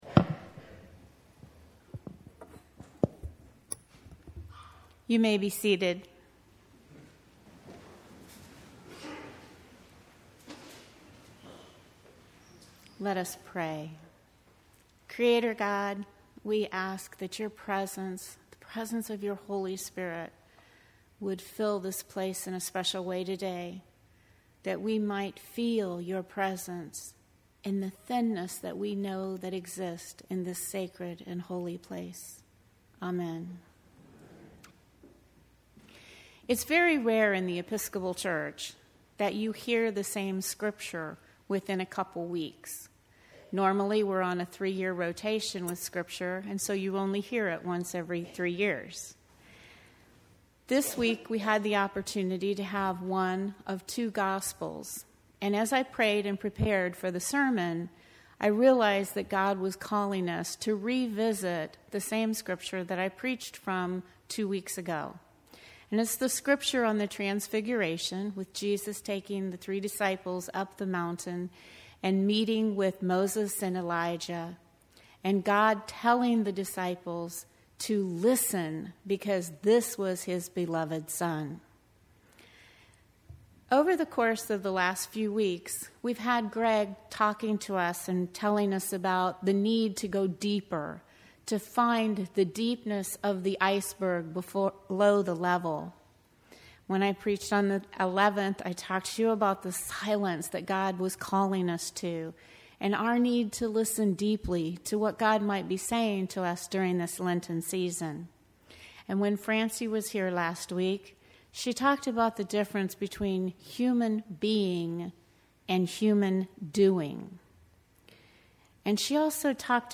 Diamonds in the Rough – A Sermon Preached at Trinity Cathedral